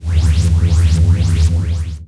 星际争霸音效-misc-uicwht00.wav